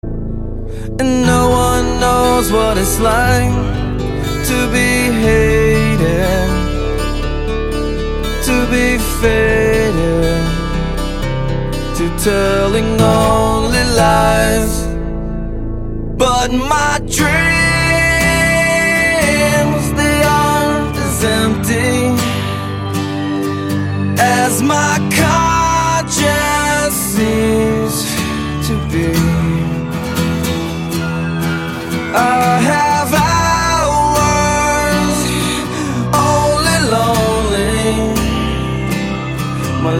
гитара , рок
баллады